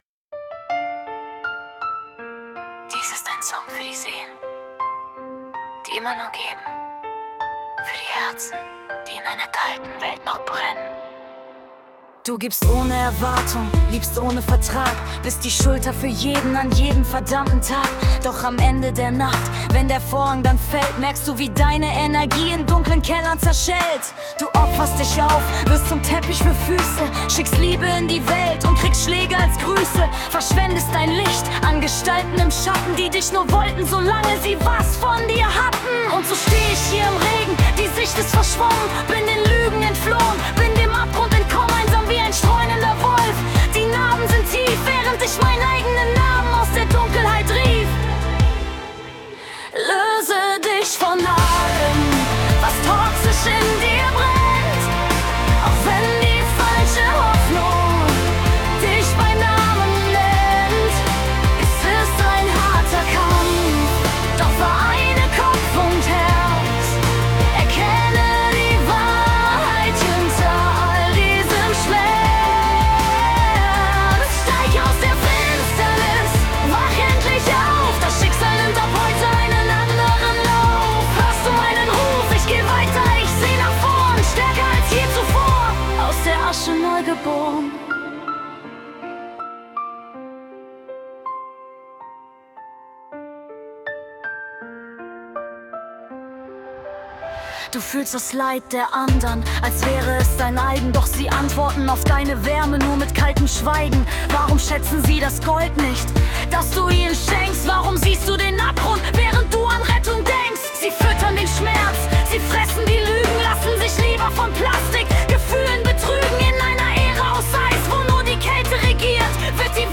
Pop, Romatic, Epic, FemaleVoice